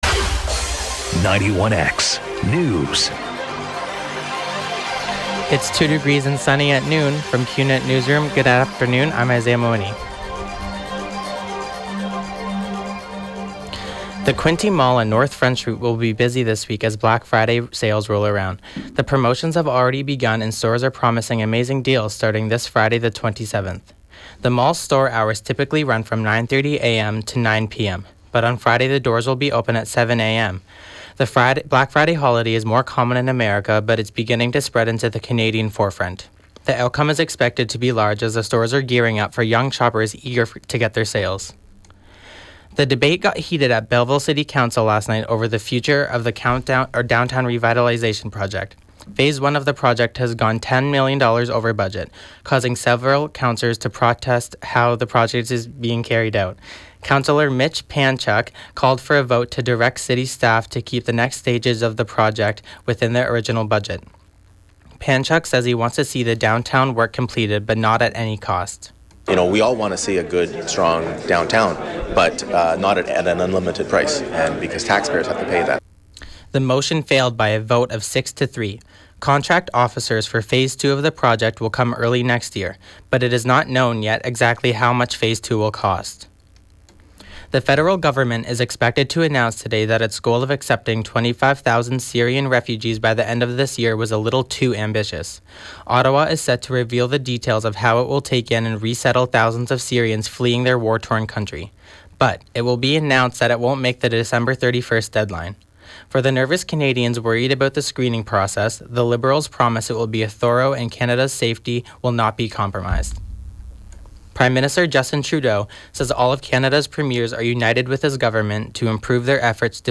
91X newscast – Tuesday, Nov. 24, 2015 – 12 p.m.